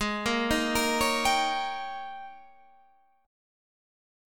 G#M7sus2sus4 chord